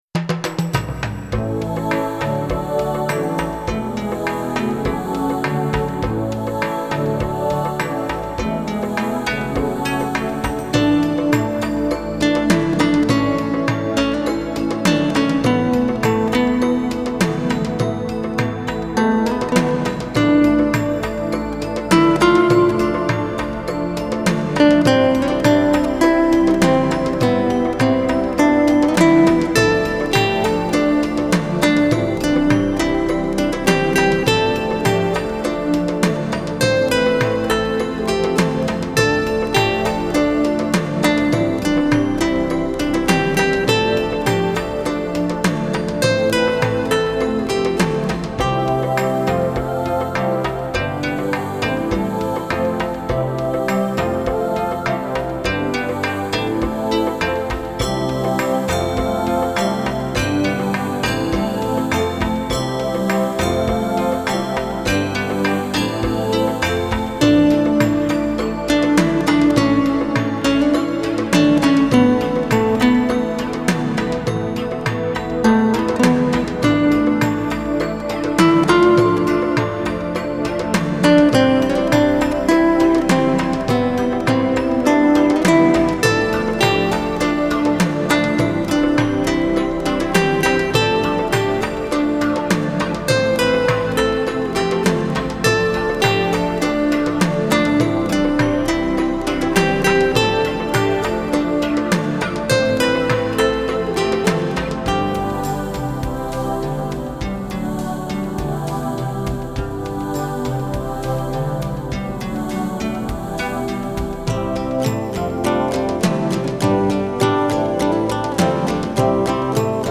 呈现比以往更精致的音乐表现，不仅层次分明，音场更为深远清晰。
以西班牙吉他、竹笛、曼陀铃，并搭配正统的南美鼓及其它多种乐器
在节奏与速度的处理比以往更为明快